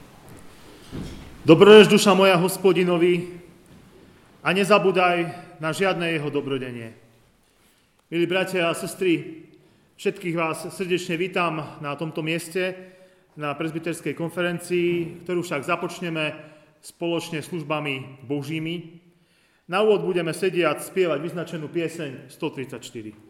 Presbyterská konferencia vo Svinici
Privítanie